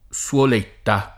[ SU ol % tta ]